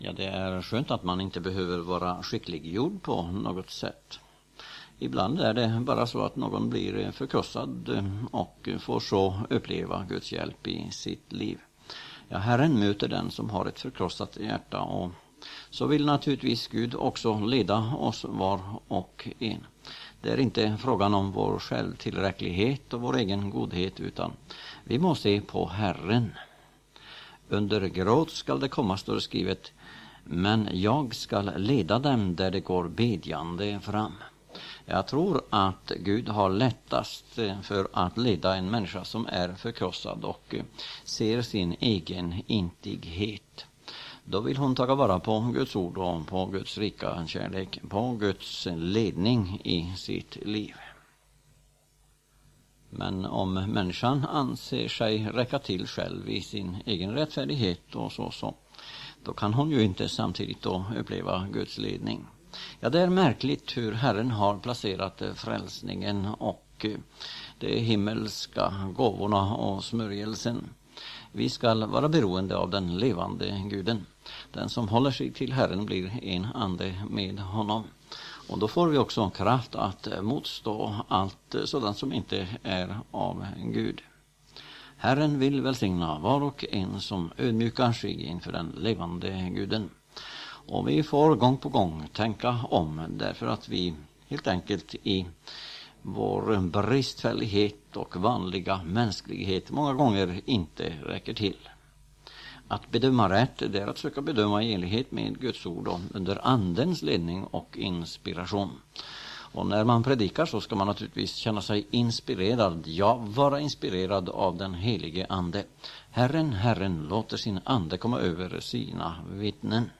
predikan_forkrosselse.mp3